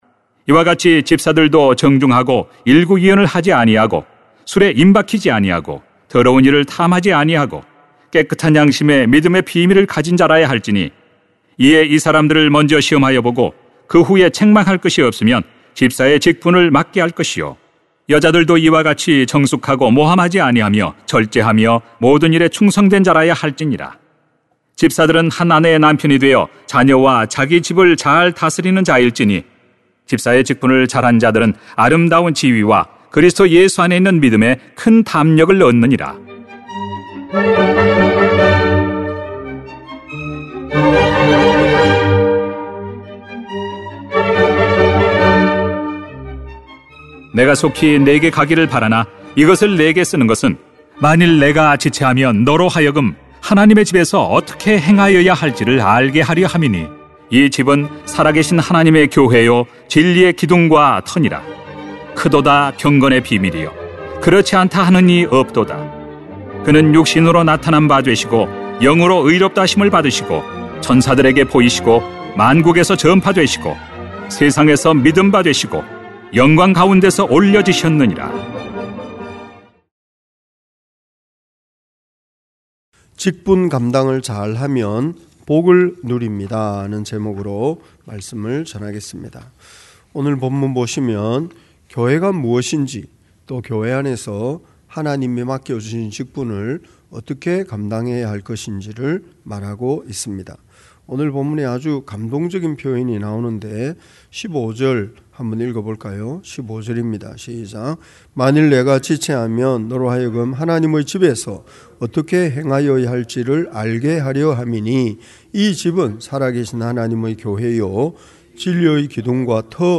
[딤전 3:8-16] 직분 감당을 잘하면 복을 누립니다 > 새벽기도회 | 전주제자교회